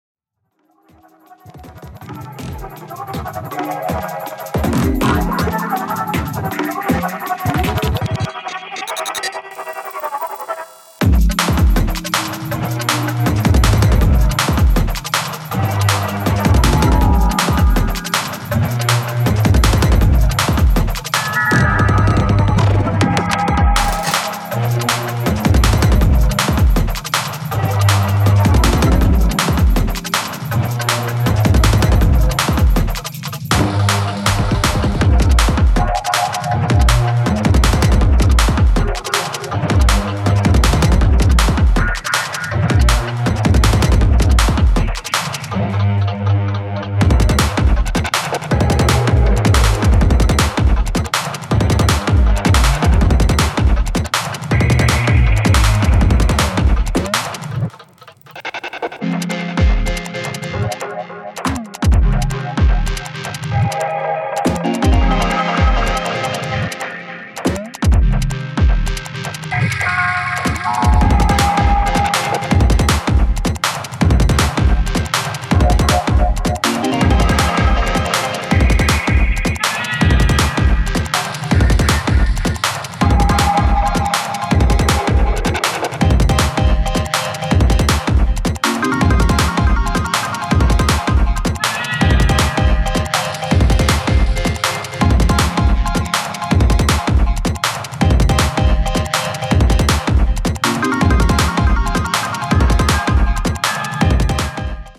Esoteric Flavored broken beats and Trancy-electro.
Techno